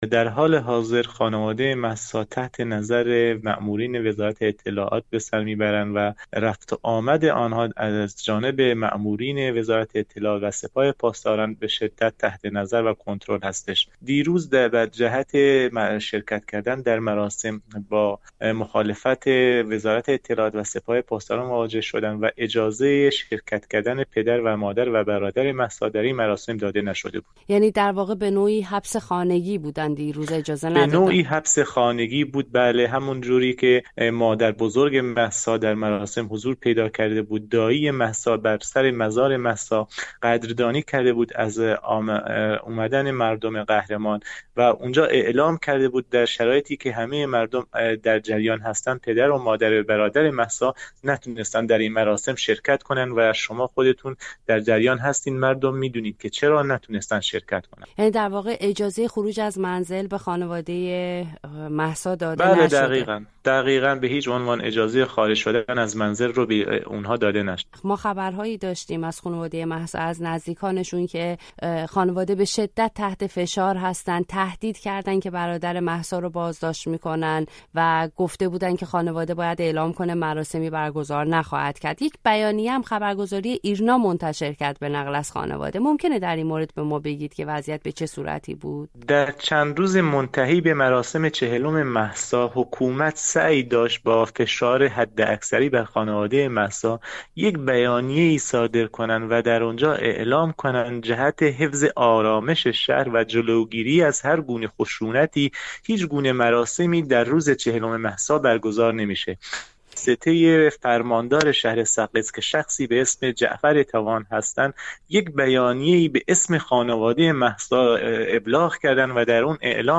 گفت‌وگوی اختصاصی با رادیوفردا